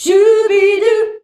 SCHUBIDU A.wav